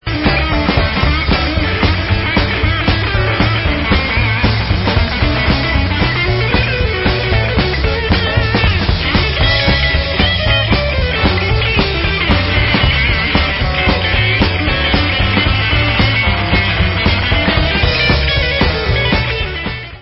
New studio album